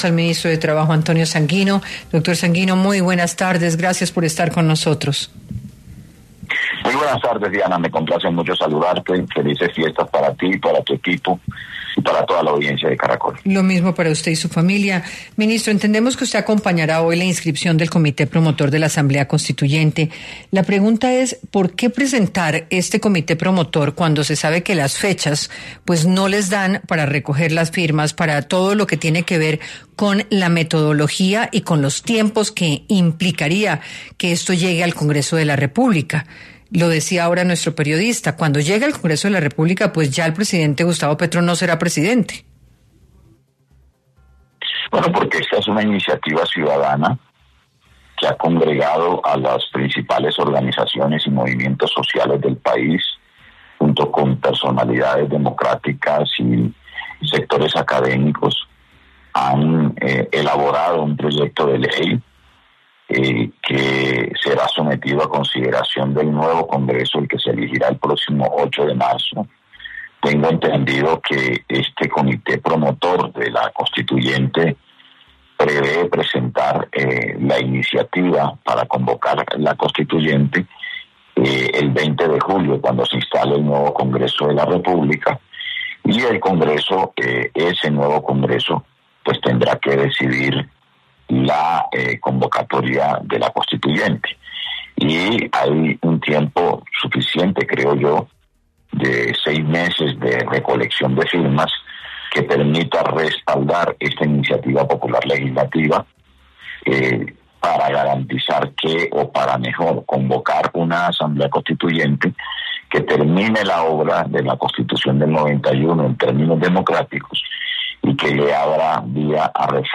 En entrevista con Caracol Radio el ministro de Trabajo, Antonio Sanguino, dio detalles sobre la inscripción del Comité promotor de Asamblea Constituyente en la Registraduría y cómo se llevará a cabo este proceso: